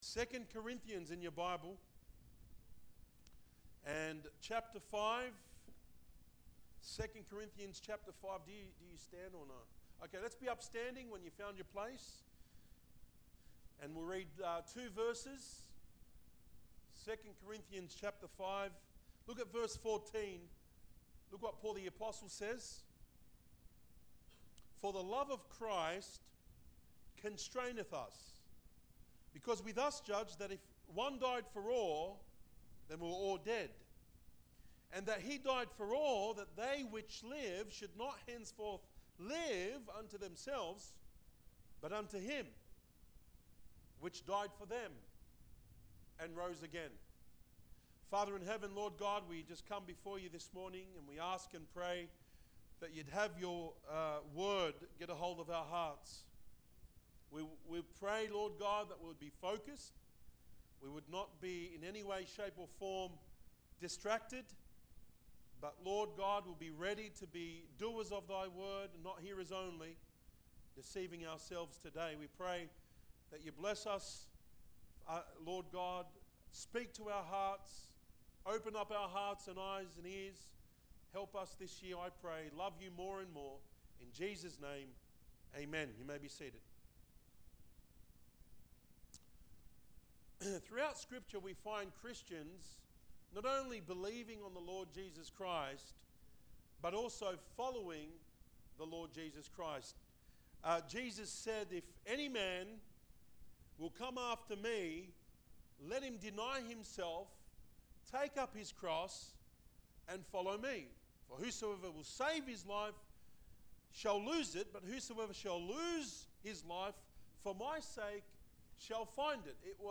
Worship Service 13/1/19 We look at the life of Paul on how he lived for Jesus.